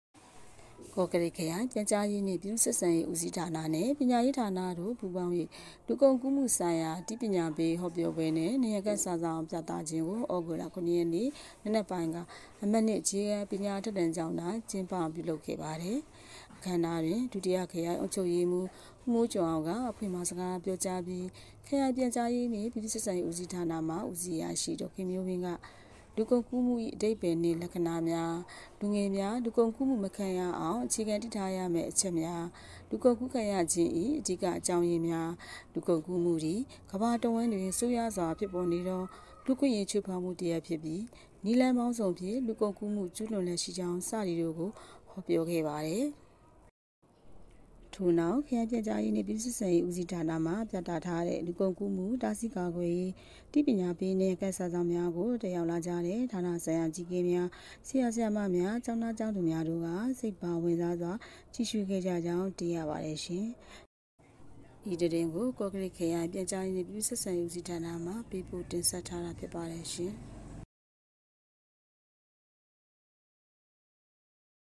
လူကုန်ကူးမှုဆိုင်ရာအသိပညာပေးဟောပြောပွဲနှင့်နံရံကပ်စာစောင်ပြသ